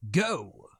Voices / Male
Go 2.wav